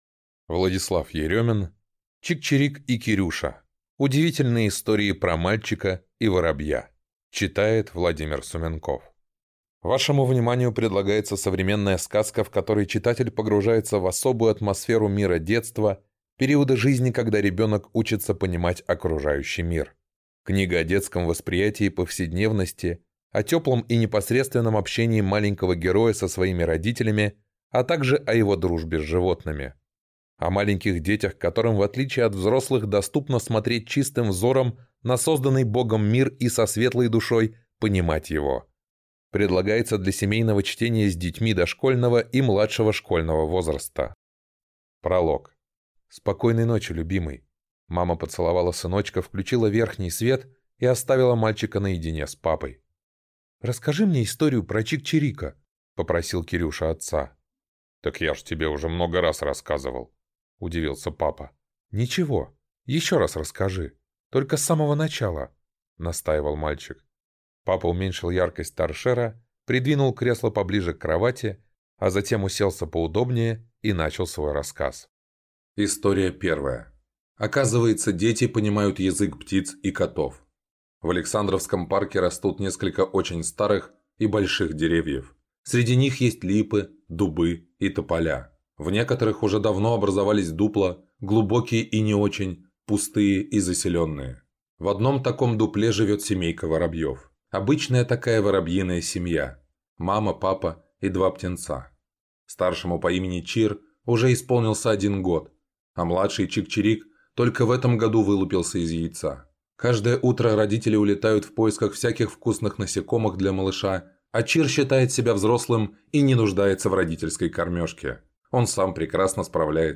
Аудиокнига Чик-Чирик и Кирюша. Удивительные истории про мальчика и воробья | Библиотека аудиокниг